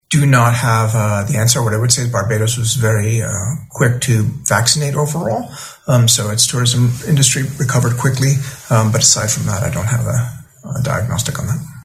Speaking this morning during the World Bank’s presentation of its regional economic review